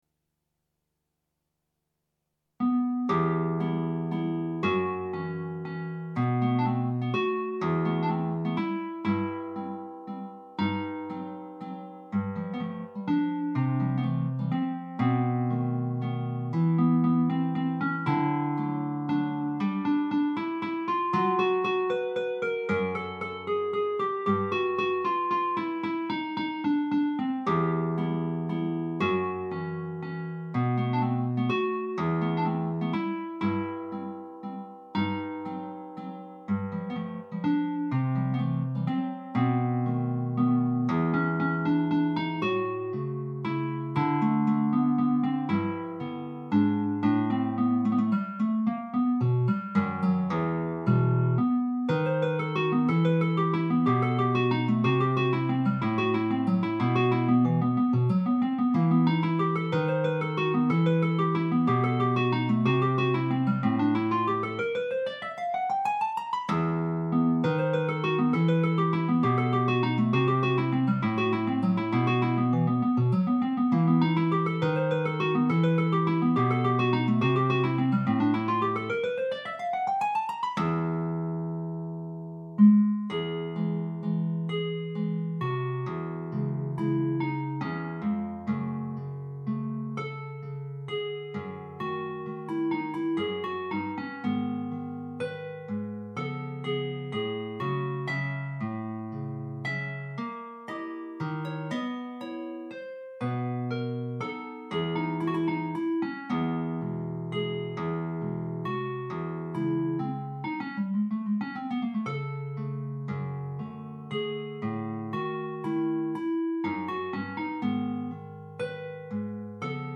Guitar Solo
Op.64 No.2. Original key C# minor.